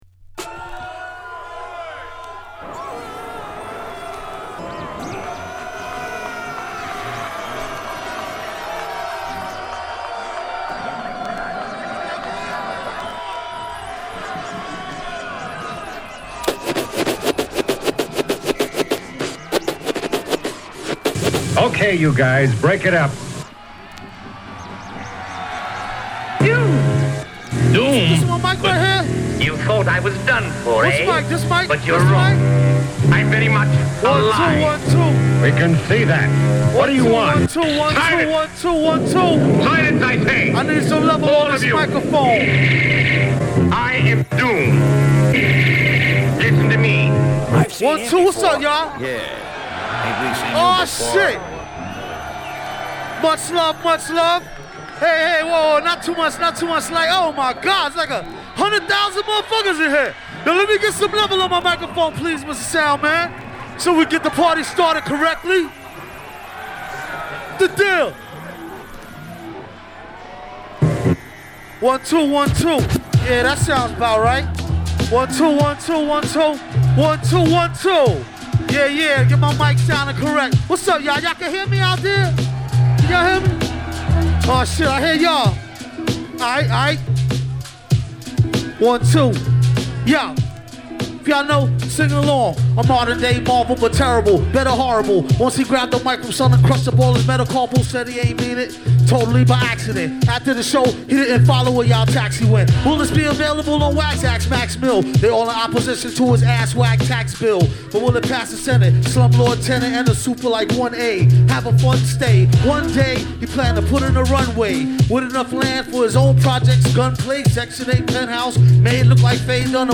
お馴染みのクラシック曲も交えて展開される圧巻のライブです！